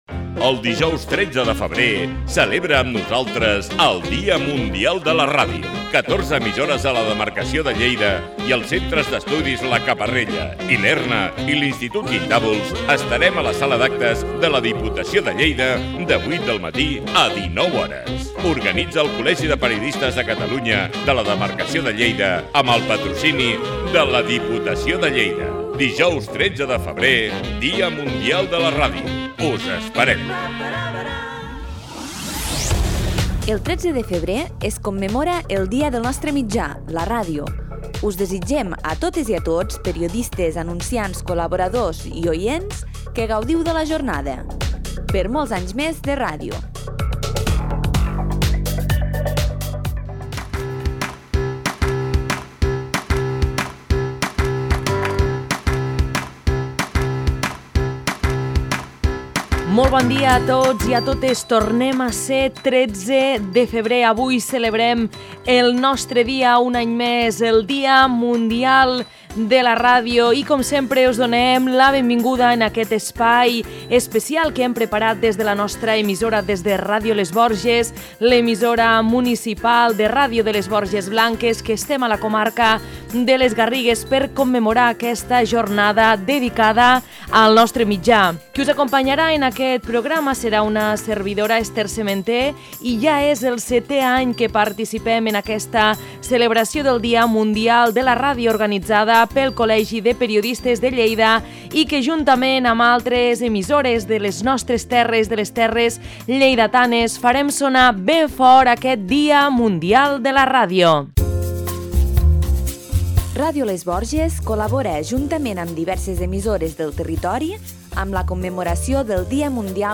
Avui, 13 de febrer, es commemora el Dia Mundial de la Ràdio 2025, i des de Ràdio les Borges hem fet un programa especial per celebrar aquesta jornada dedicada al nostre mitjà, que s'ha emès durant la jornada de 12 hores en directe de ràdio que, enguany, han tingut lloc a la sala d'actes de la Diputació de Lleida, organitzada pel Col·legi de Periodistes de Catalunya-Demarcació de Lleida per desè any consecutiu.